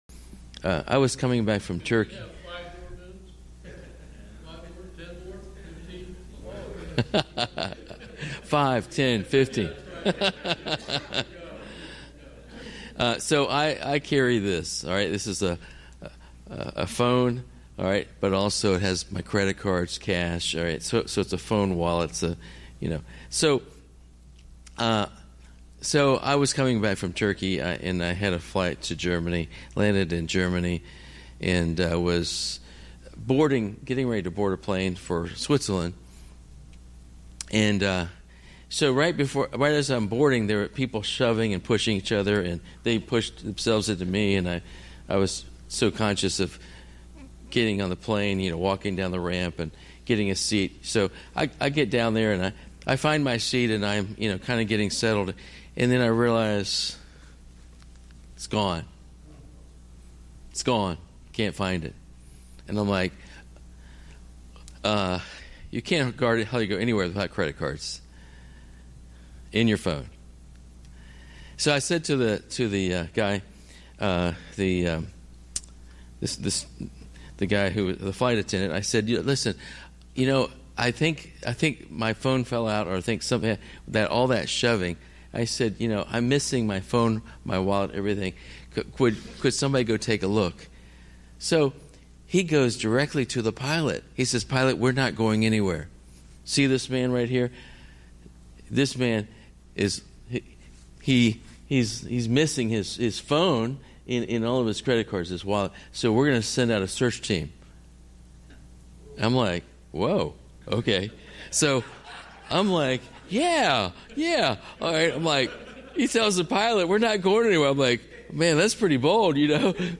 Men's Breakfast